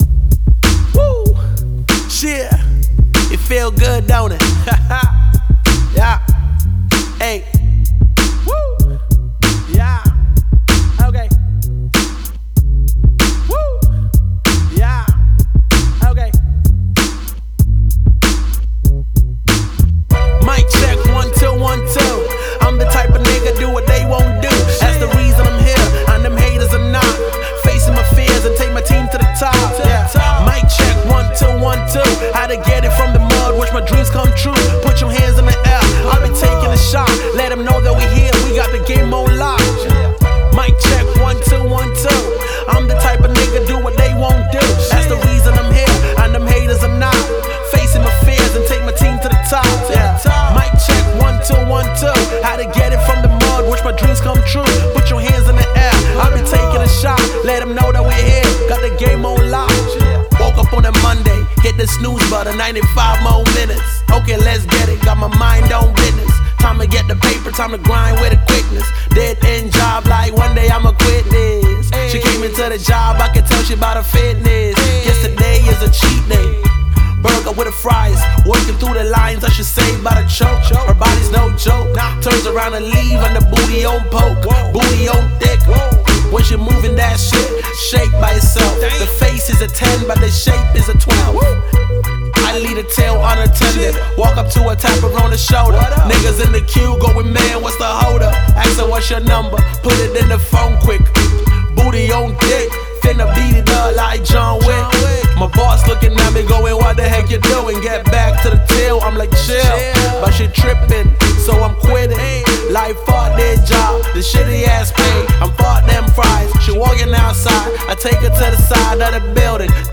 Hiphop
Description : Old school hip hop for today's listening ear.